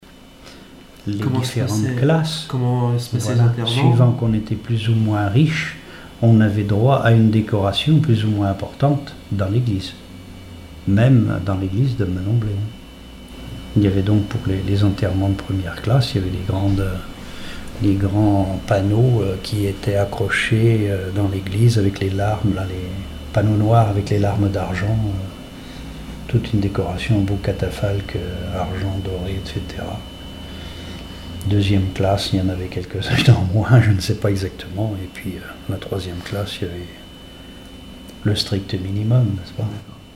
Témoignages ethnologiques et historiques
Catégorie Témoignage